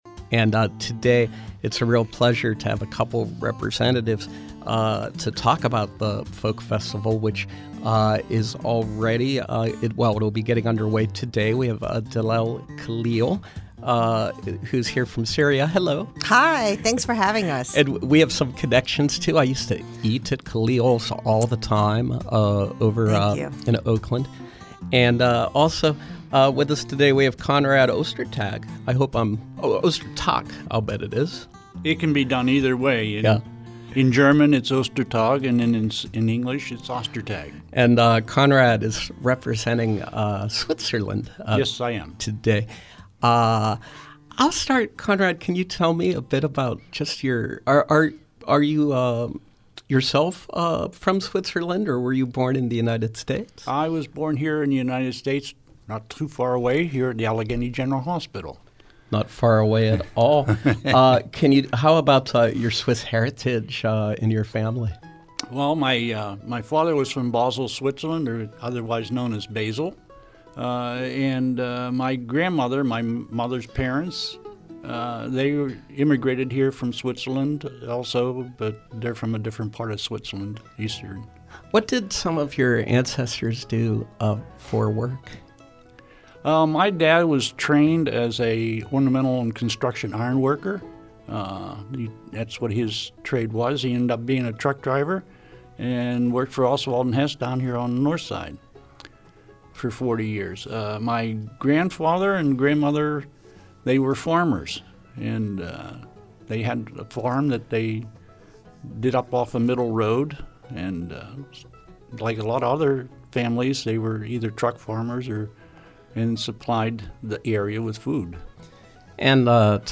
Interview: Pittsburgh Folk Festival
with an instrumental performance and details on the Pittsburgh Folk Festival, 9/3 and 9/4, Schenley Tent Plaza.